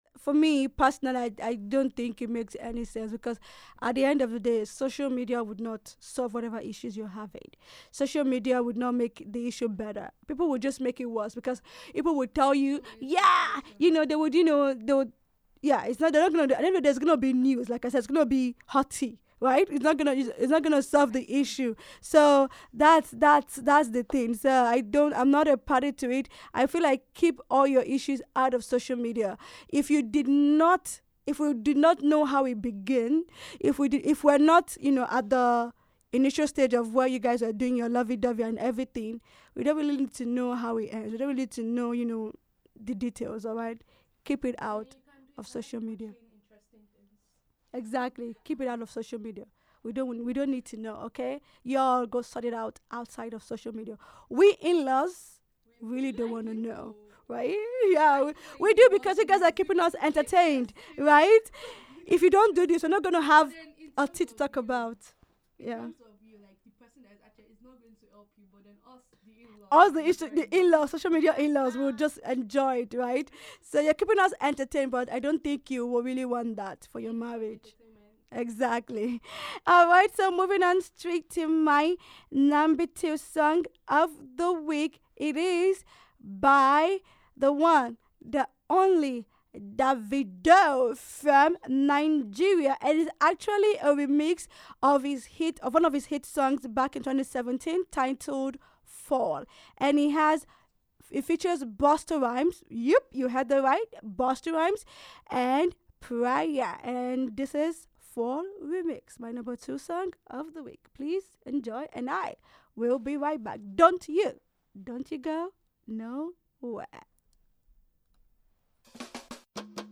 International, World-Beat, Hip-Hop, Indie, Pop